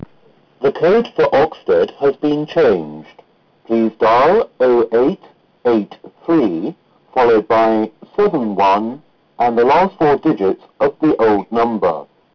Oxted announcement, source as above 267k